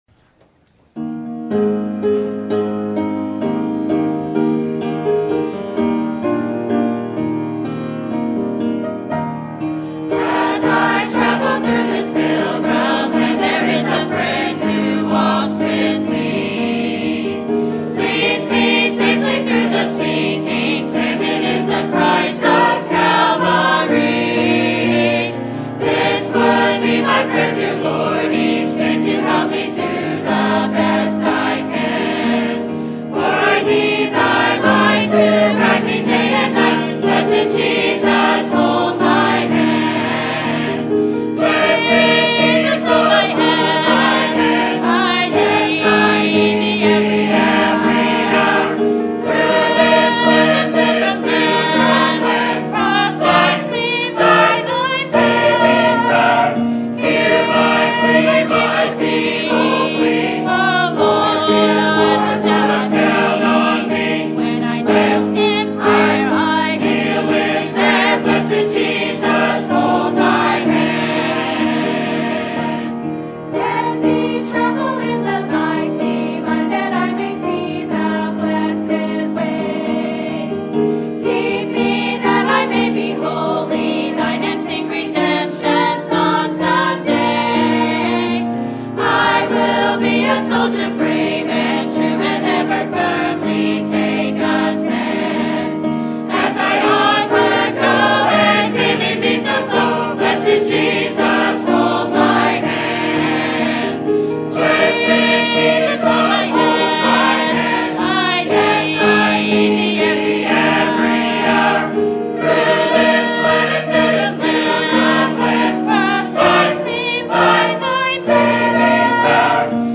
Sermon Topic: 50th Anniversary Sermon Type: Special Sermon Audio: Sermon download: Download (30.35 MB) Sermon Tags: Leviticus Anniversary Celebration Jubile